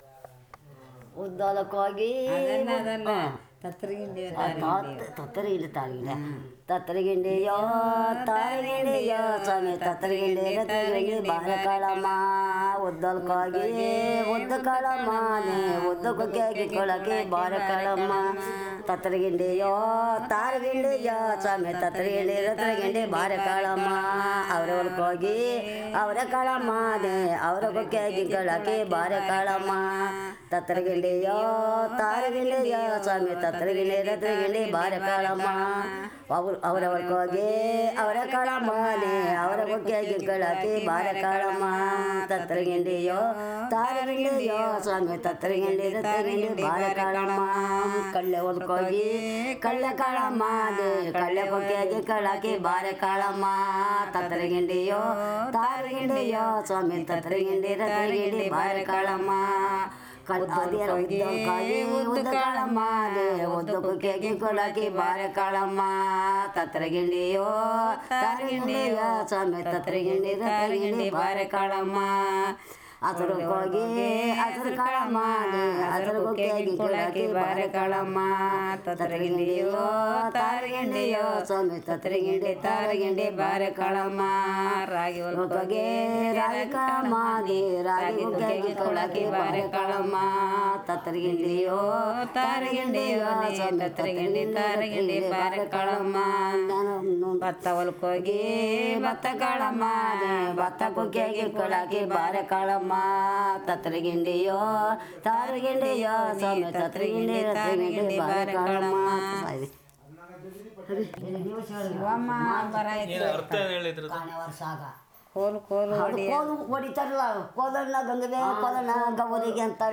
Performance of folk song 'Thatargindi taregindi'
Performed as a part of the Ugadi festival and also sung during Kolata folk dance by JenuKuruba community. The song narrates tribe's journey to a harvesting field and their request for crops such as paddy, ragi, black grams, and groundnuts from the farmer.